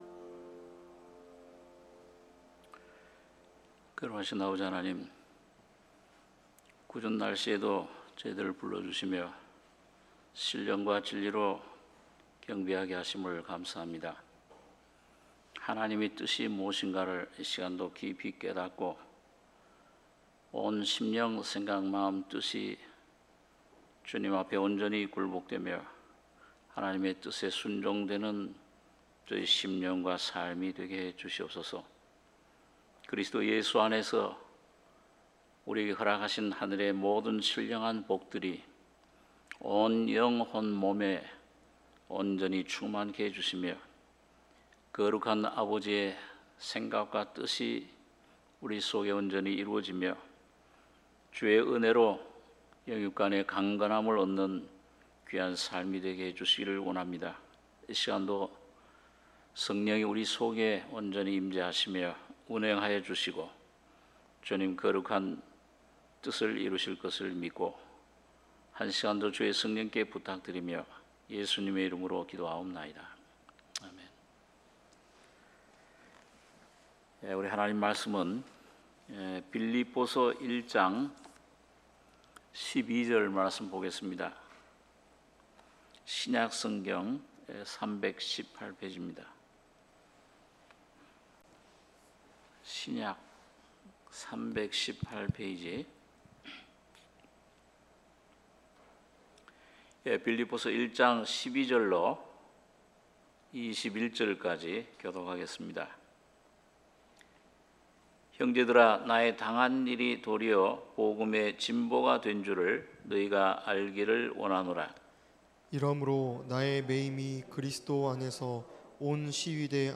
수요예배 빌립보서 1장 12-21절